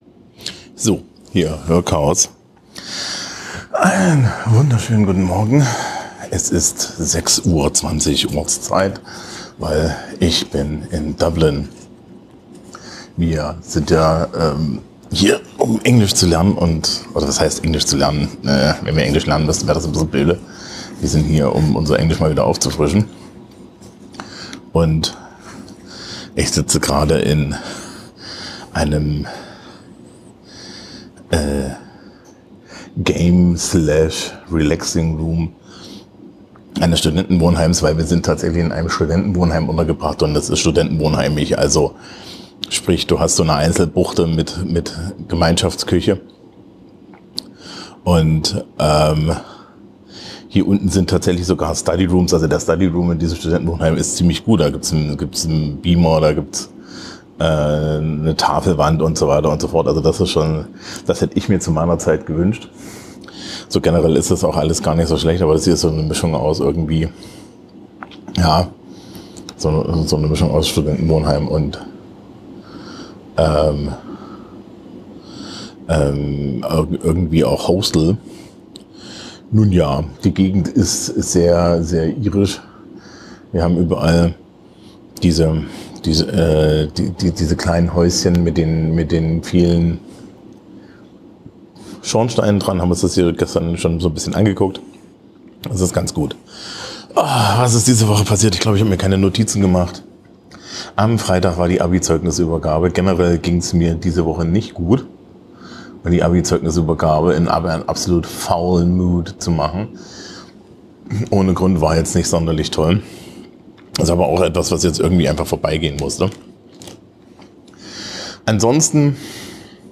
Ich bin in Dublin und es ist sehr früh. Also alles etwas leise.